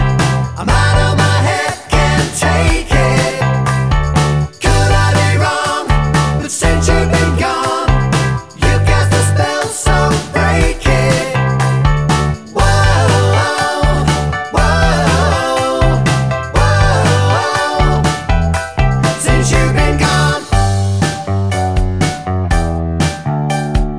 Minus Lead Guitar Rock 3:26 Buy £1.50